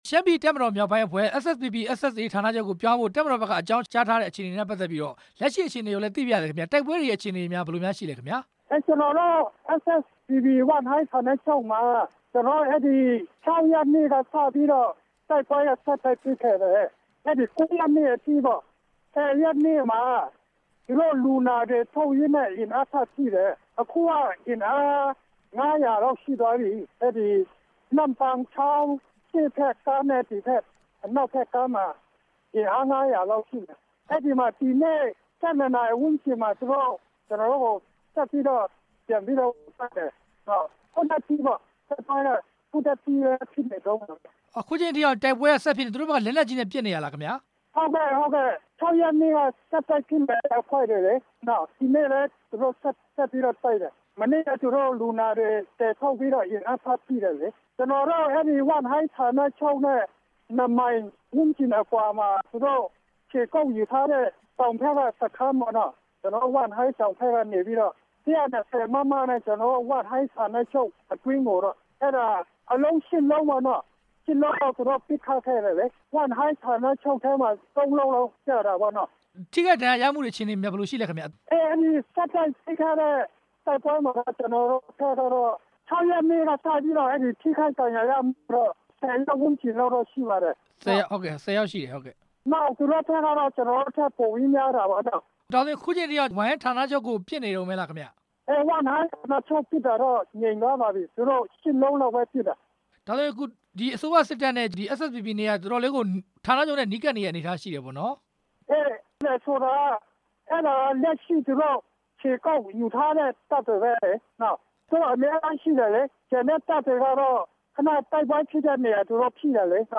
SSPP/SSA ဝမ်းဟိုင်းဋ္ဌာနချုပ်ကို စစ်တပ်က ပစ်ခတ်တဲ့ အကြောင်း မေးမြန်းချက်